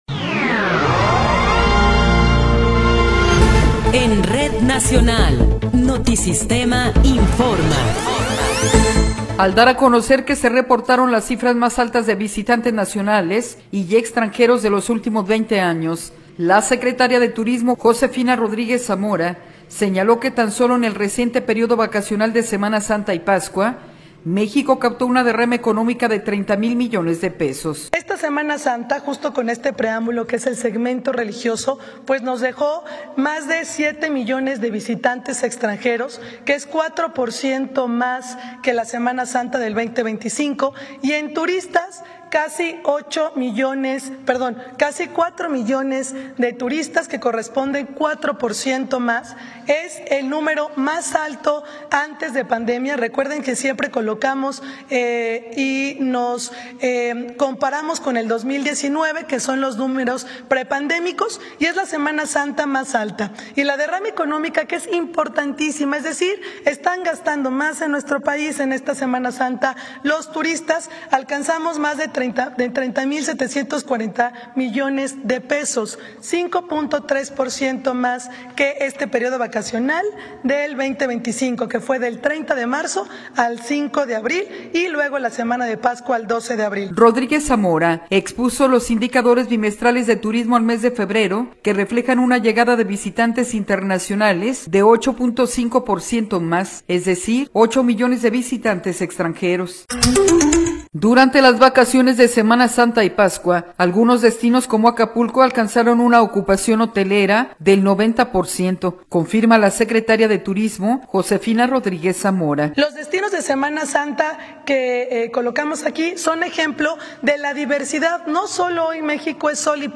Noticiero 21 hrs. – 26 de Abril de 2026